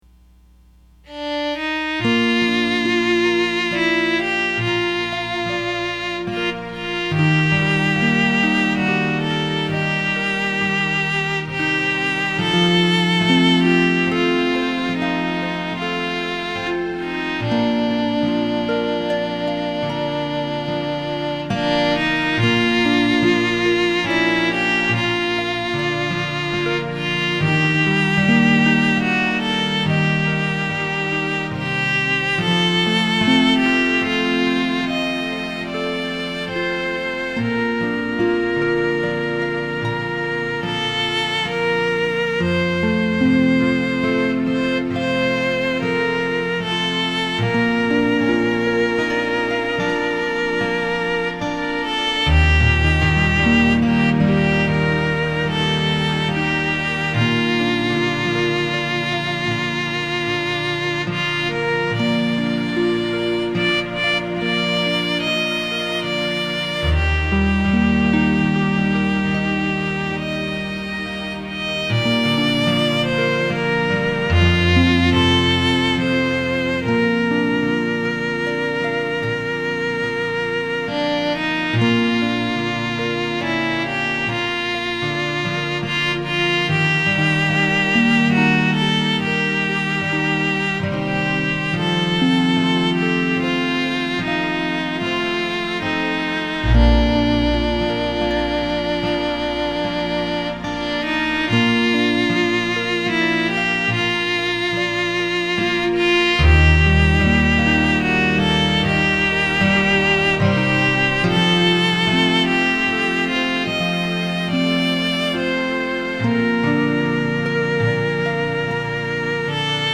hymns played on the Harp with background music to include
violin, flute and orchestra.